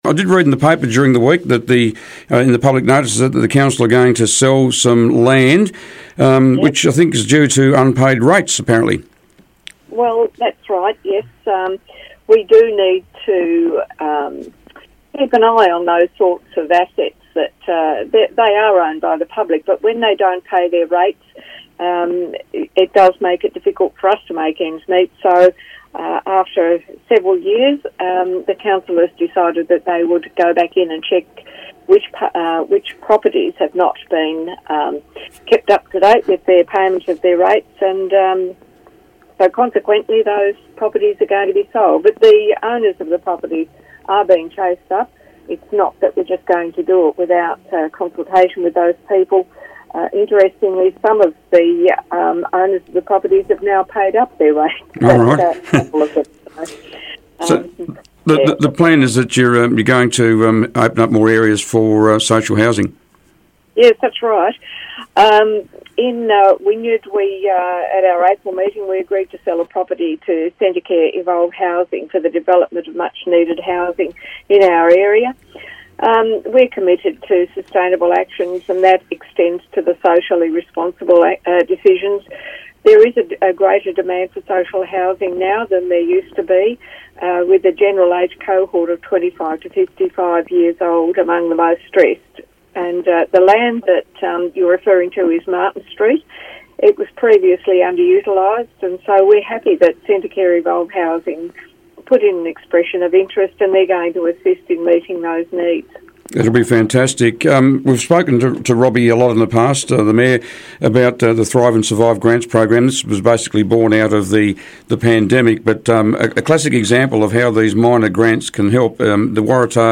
Acting Waratah-Wynyard Mayor Mary Duniam was today's Mayor on the Air.